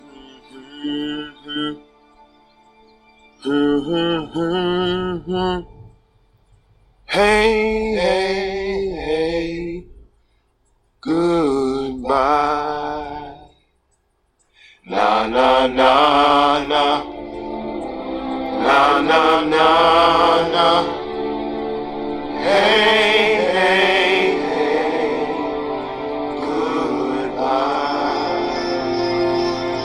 Category: Sports   Right: Personal